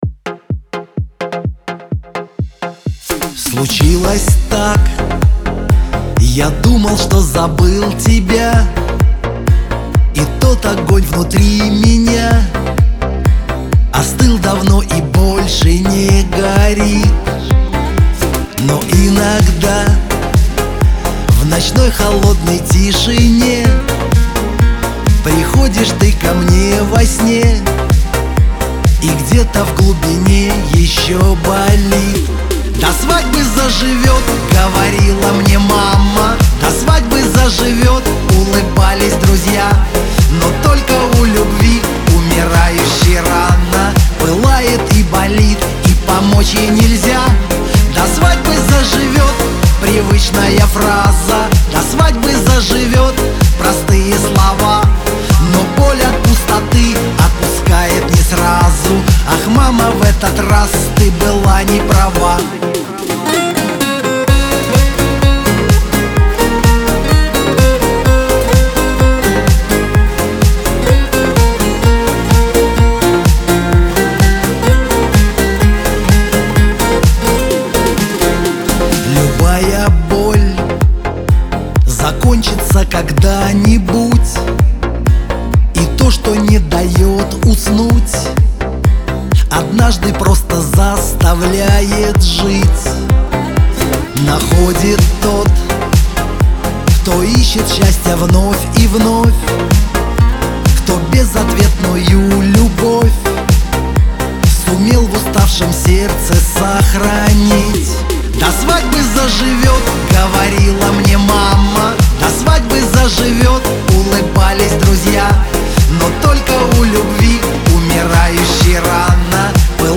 диско , эстрада , pop